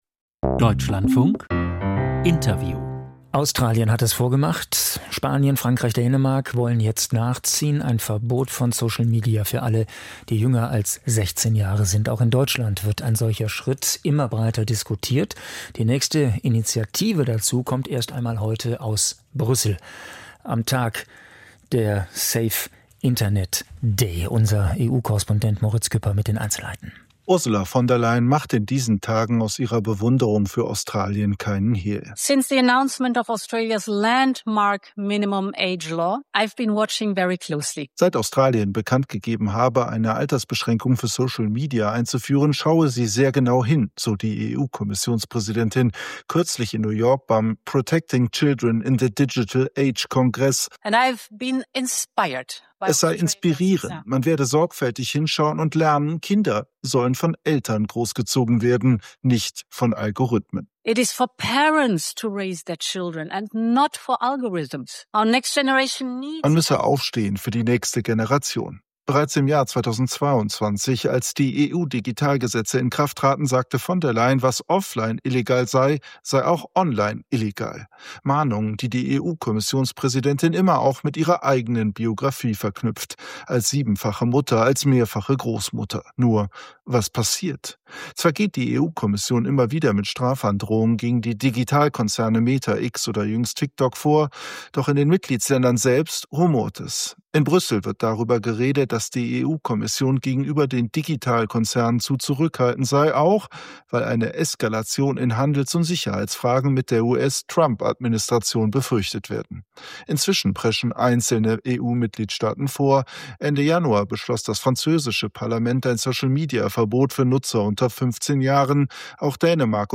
Cybermobbing, Interview